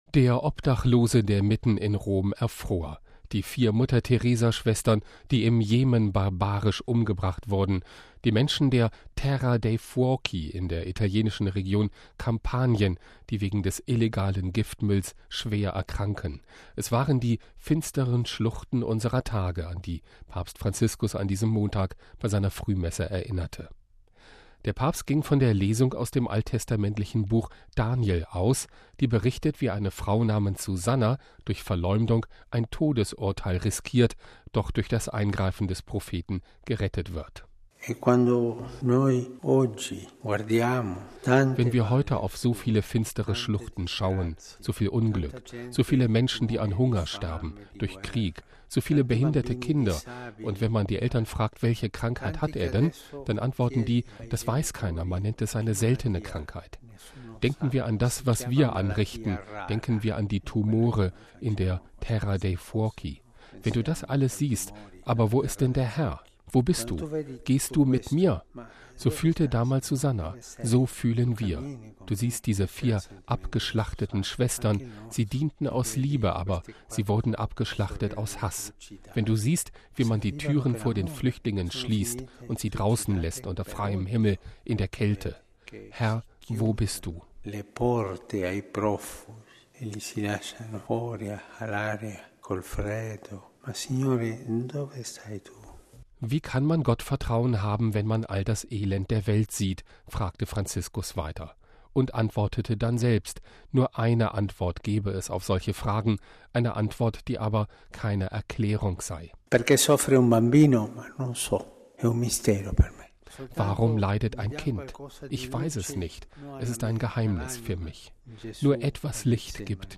Es waren die „finsteren Schluchten“ (vgl. Psalm 23) unserer Tage, an die Papst Franziskus an diesem Montag bei seiner Frühmesse erinnerte.